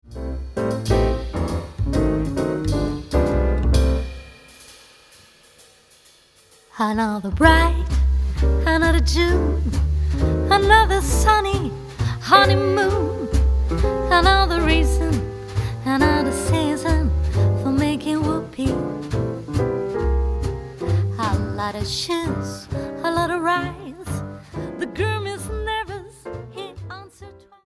voice
piano
bass
drums